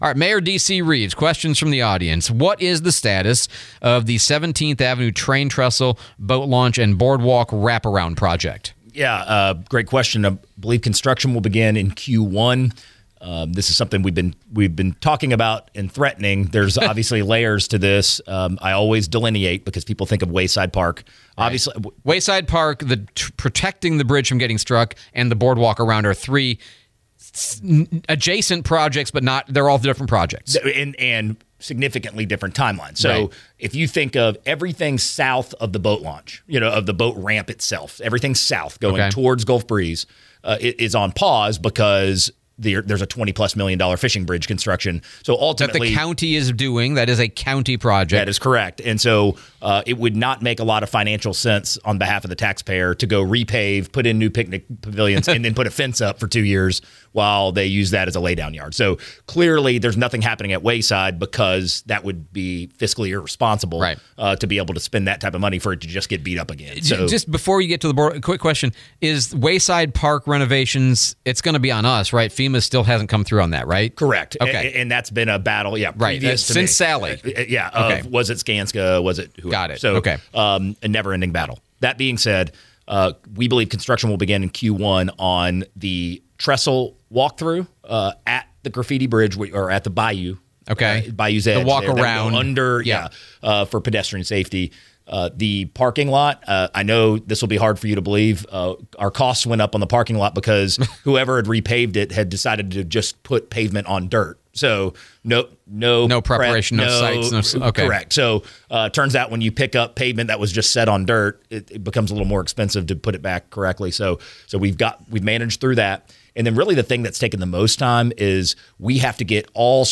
12/17/25 Pensacola Mayor DC Reeves Town Hall